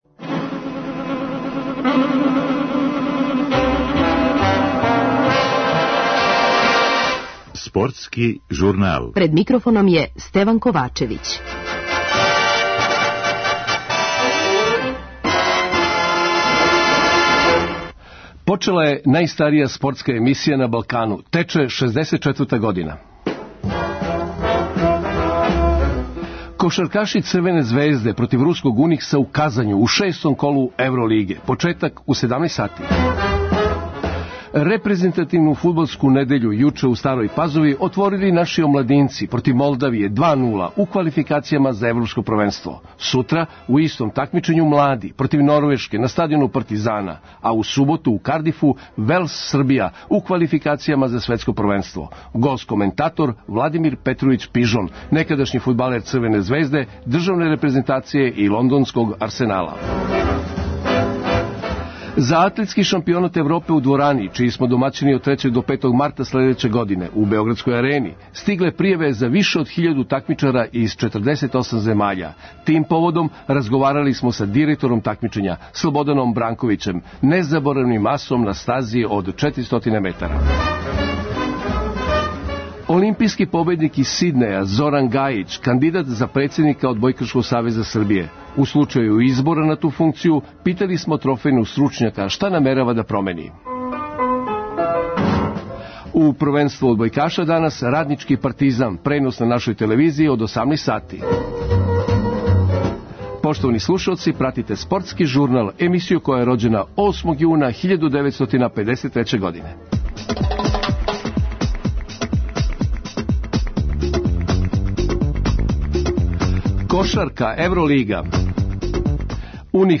Гост коментатор - Владимир Петровић Пижон, некадашњи фудбалер Црвене звезде, државне репрезентације и лондоснког Арсенала.
За Атлетски шампионат Европе у дворани, чији смо домаћини од 3. до 5. марта следеће године у Београдској арени, стигле пријаве за више од хиљаду такмичара из 48 земаља. Тим поводом разговарали смо са директором такмичења, Слободаном Бранковићем, незаборавним асом са стазе од 400 метара.